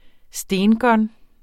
Udtale [ ˈsdεnˌgʌn ]